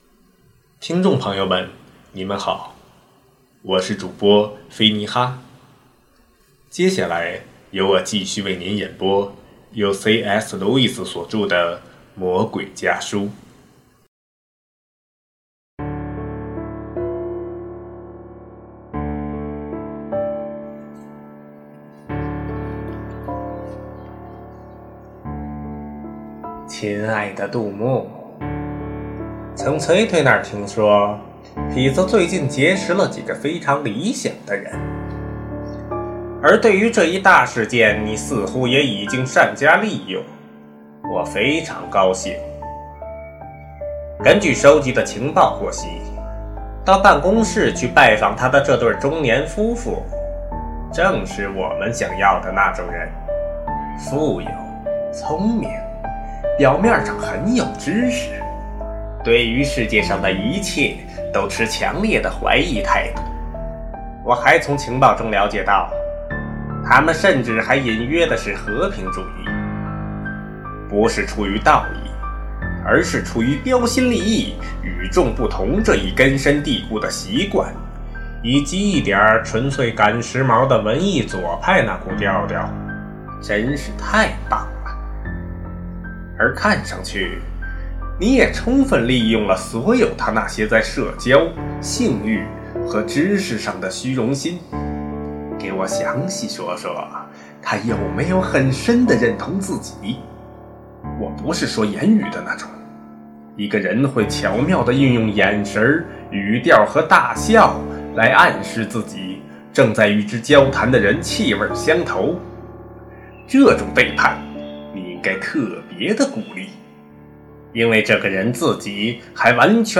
首页 > 有声书 | 灵性生活 | 魔鬼家书 > 魔鬼家书：第十封书信